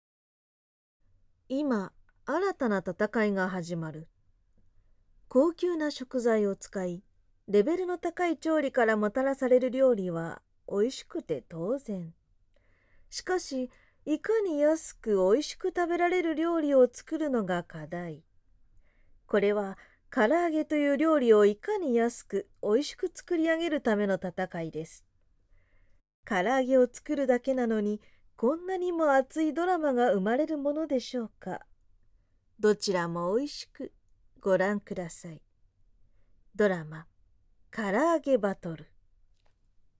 ボイスサンプル
落ち着いたナレーション